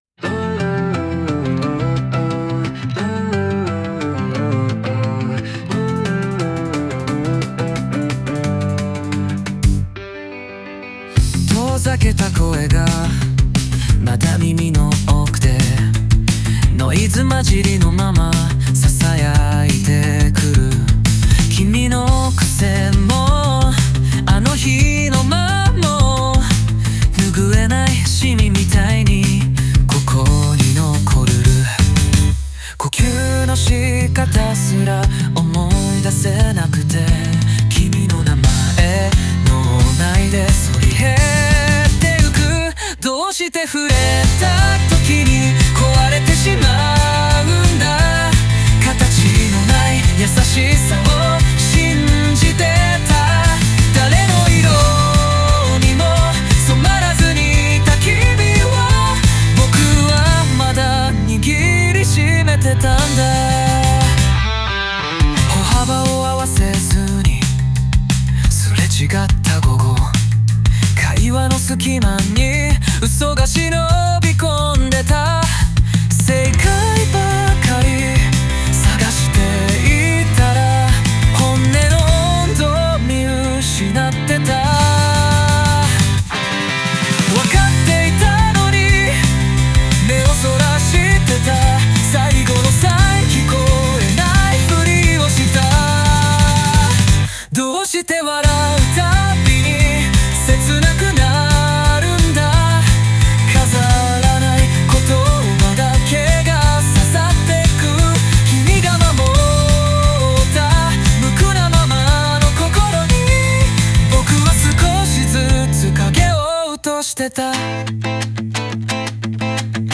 邦楽男性ボーカル著作権フリーBGM ボーカル
男性ボーカル邦楽邦楽 男性ボーカルポップスアップテンポ青春切ないノスタルジック
男性ボーカル（邦楽・日本語）曲です。